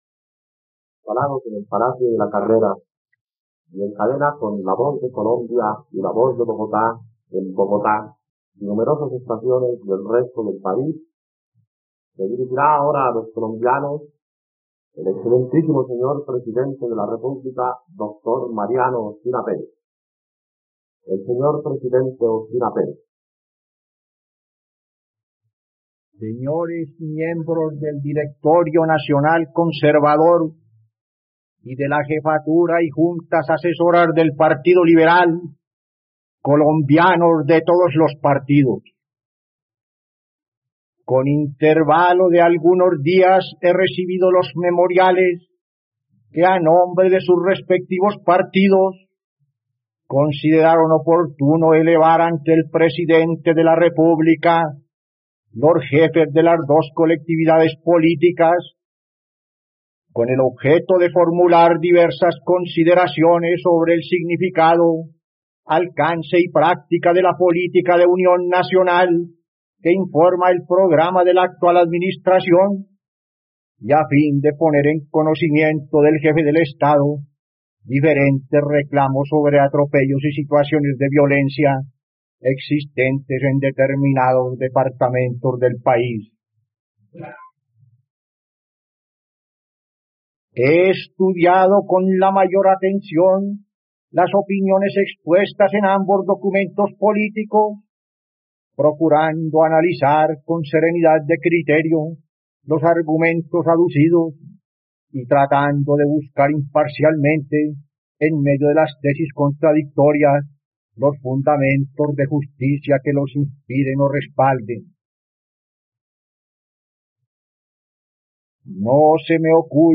..Escucha ahora los memoriales de los partidos políticos en boca del presidente Mariano Ospina Pérez en la plataforma de streaming de los colombianos: RTVCPlay.
discurso político